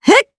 Ripine-Vox_Attack3_jp.wav